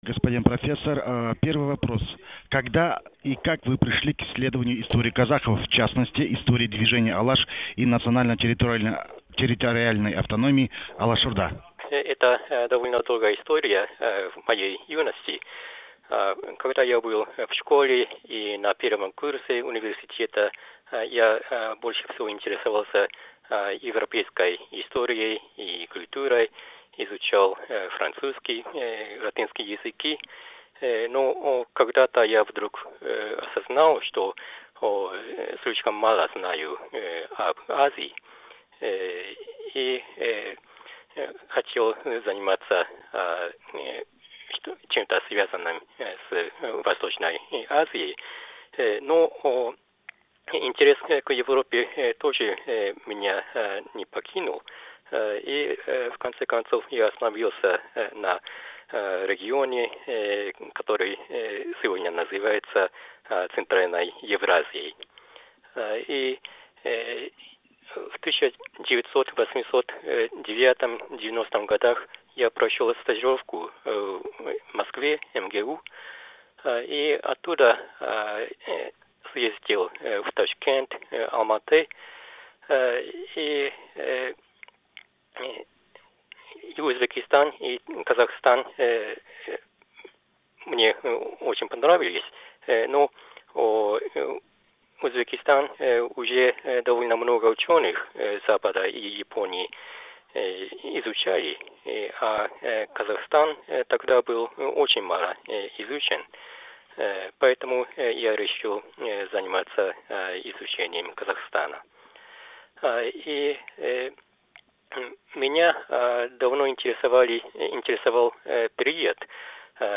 Аудиозапись интервью